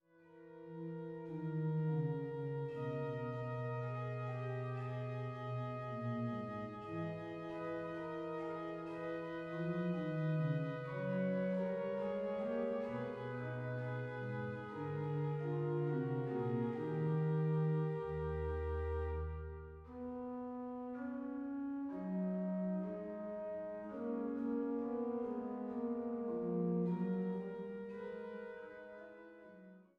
Rötha/St. Georgen